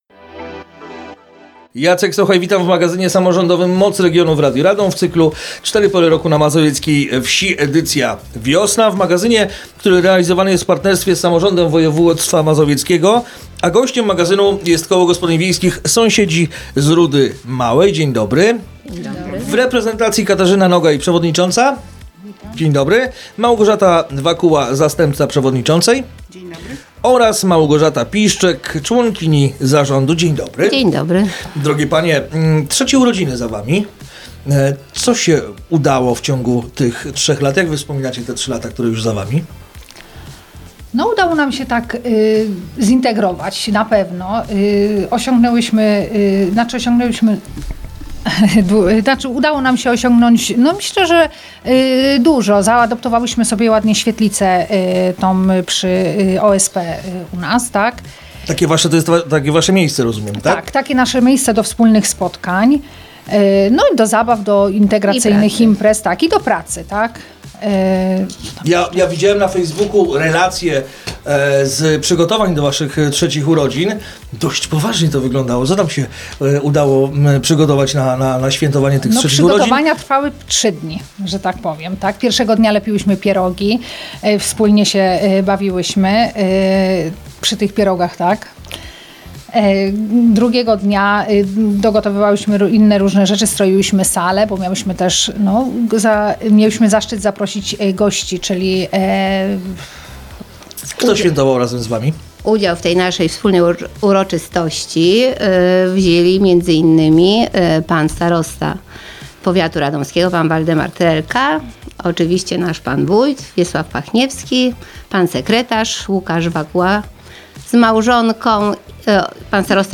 Rozmowa dostępna jest także na facebookowym profilu Radia Radom: